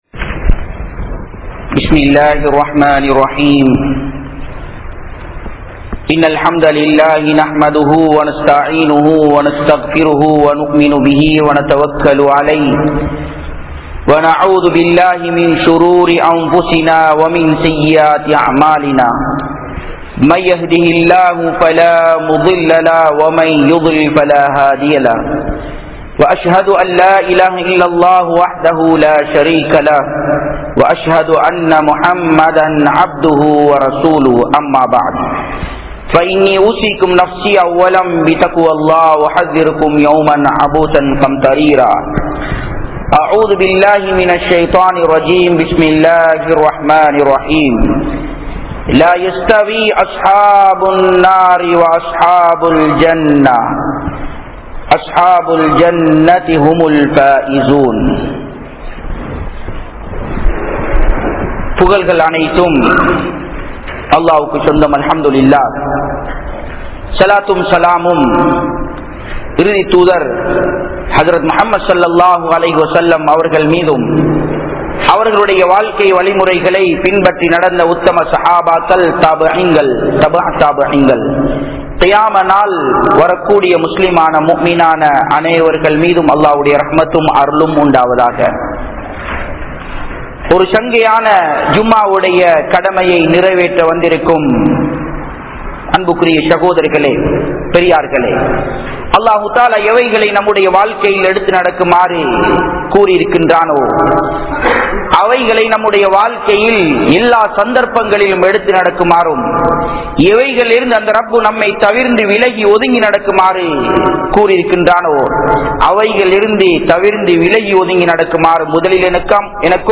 Marumaiel VIP Yaar? (மறுமையில் VIP யார்?) | Audio Bayans | All Ceylon Muslim Youth Community | Addalaichenai